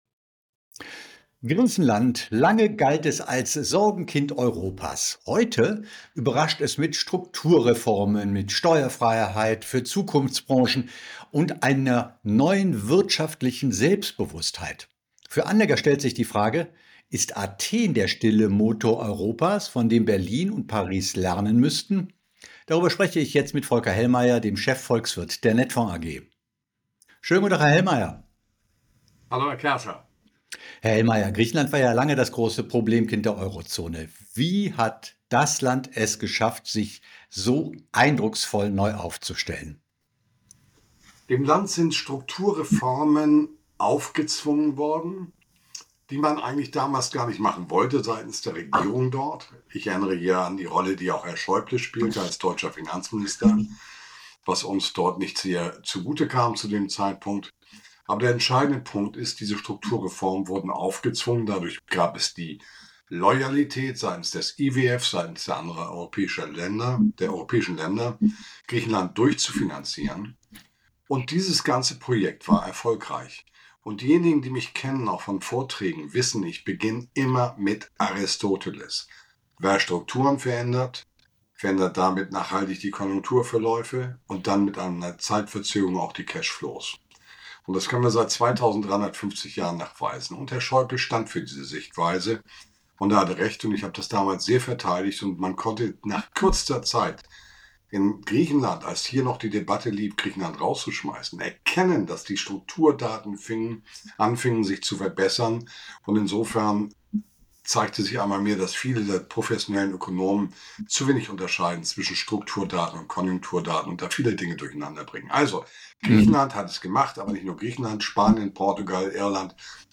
Beschreibung vor 6 Monaten Griechenland galt lange als Krisenstaat Europas – heute überrascht das Land mit Strukturreformen, Steueranreizen und wachsendem Selbstbewusstsein. Im Gespräch